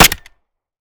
Home gmod sound weapons papa320
weap_papa320_fire_last_plr_mech_04.ogg